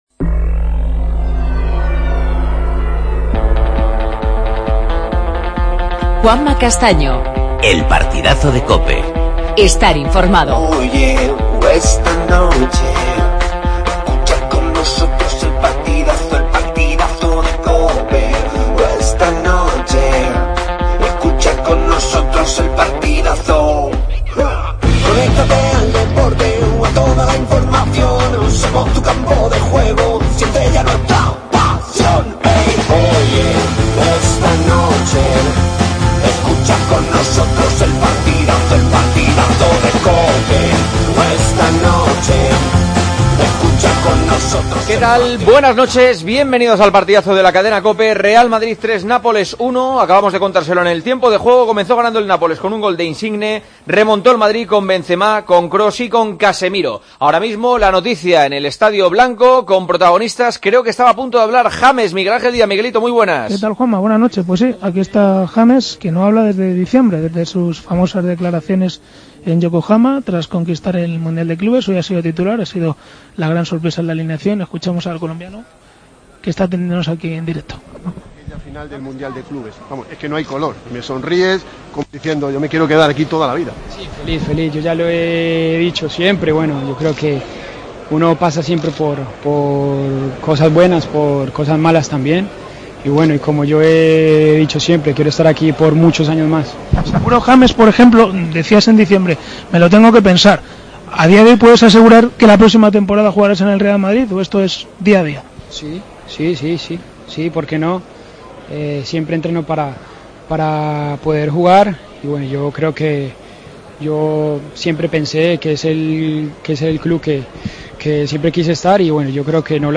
Escuchamos a James, Zidane, Reina, Ramos, Casemiro y Benzema.